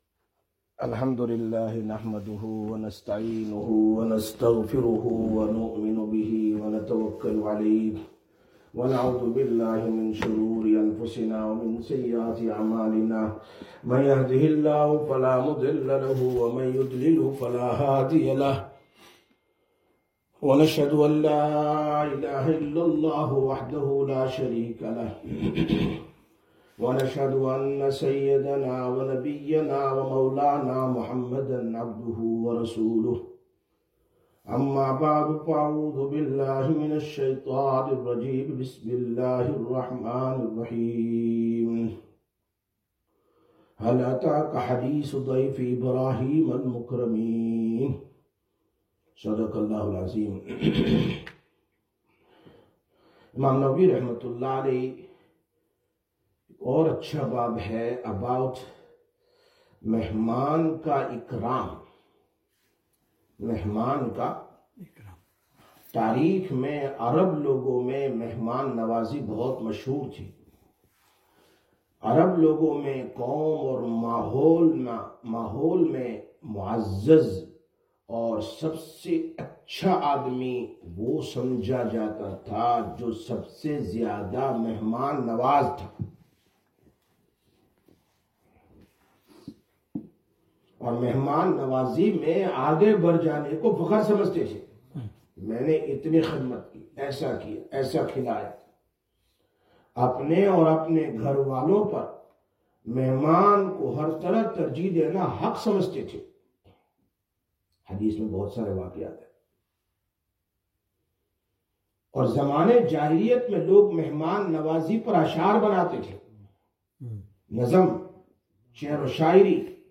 01/04/2026 Sisters Bayan, Masjid Quba